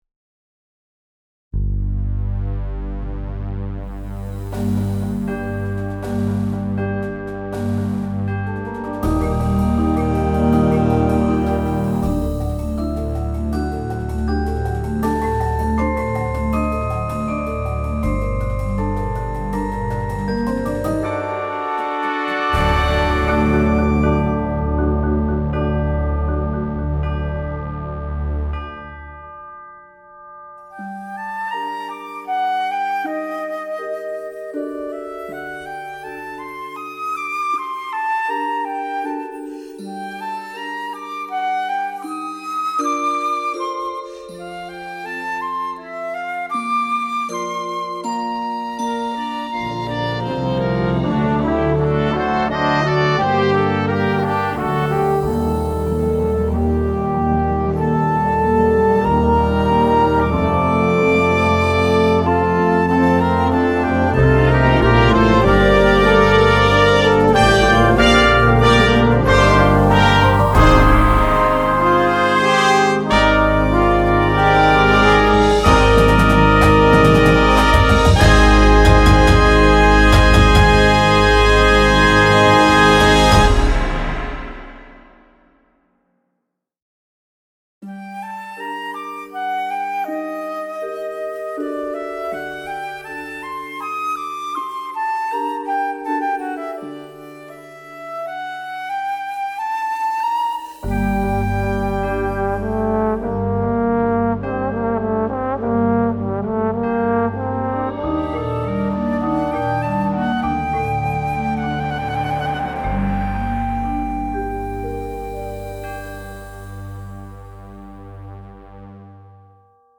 Mvt. 2 (LIVE)